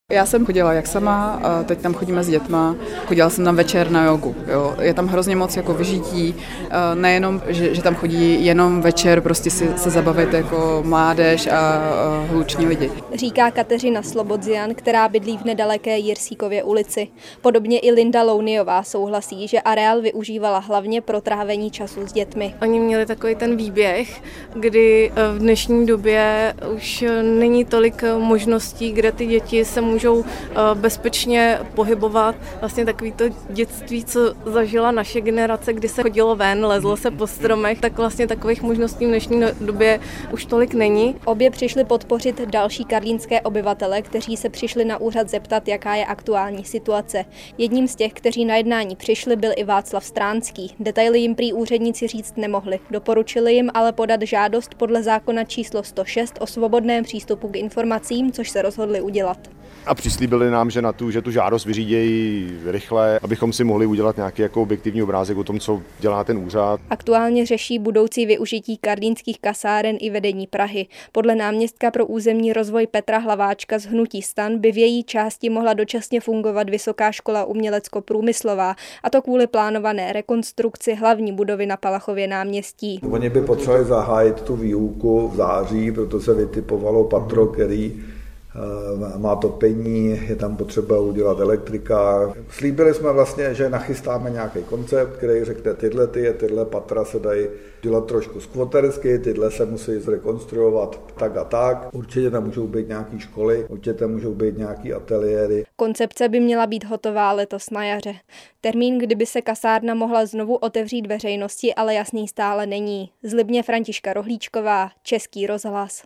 Zprávy Českého rozhlasu Střední Čechy: Pražská zoo představila nové tasmánské čerty. Samec Durin je v Praze dokonce největší chovaný ďábel - 14.02.2025